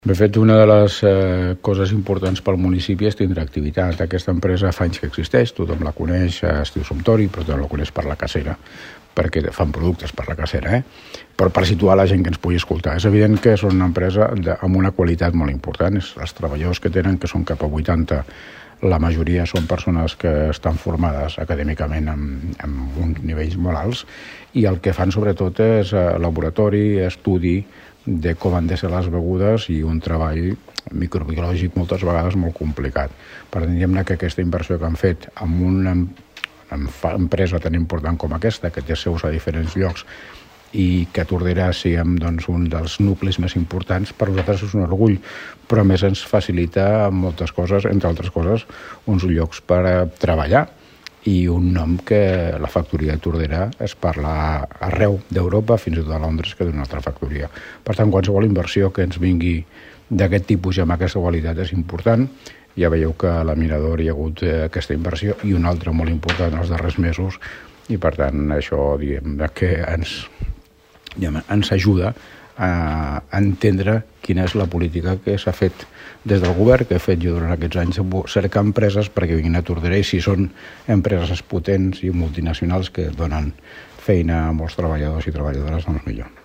L’Alcalde de Tordera, Joan Carles Garcia recorda que és una empresa consolidada al municipi, i  que decideixi seguir invertint a Tordera és un orgull pel municipi.
Alcalde-Suntory.mp3